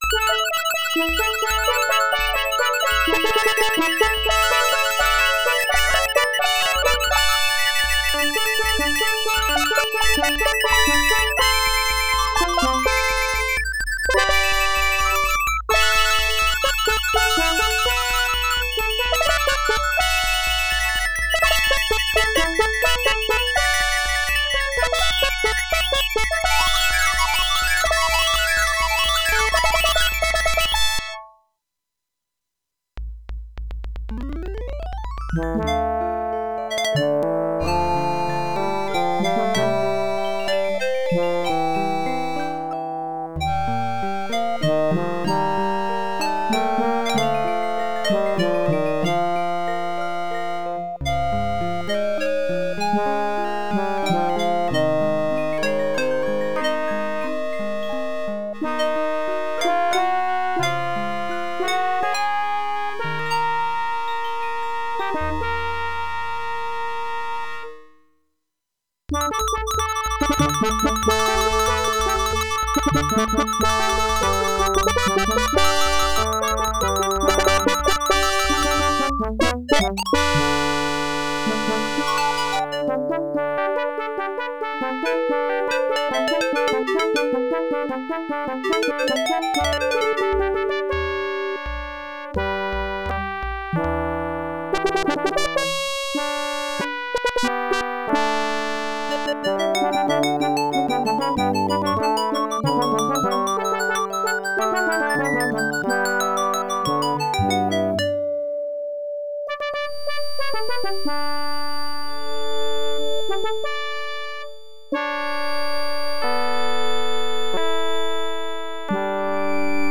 Category:Music Files